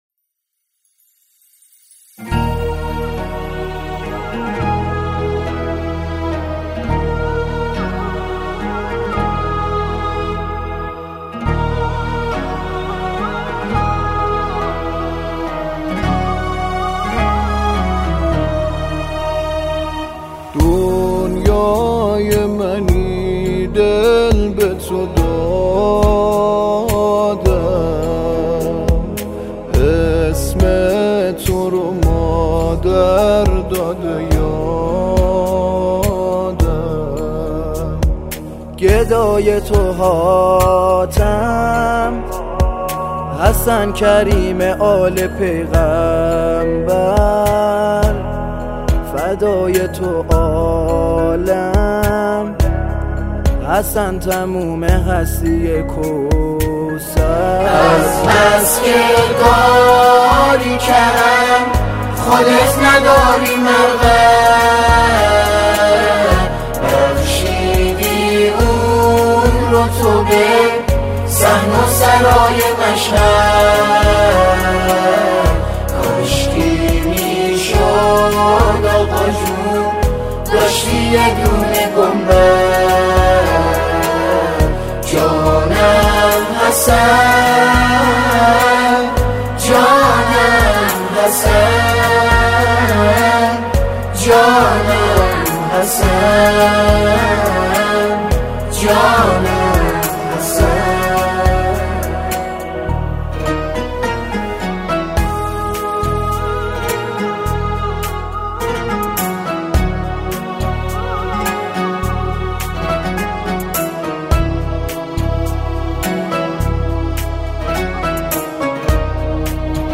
با نوای دلنشین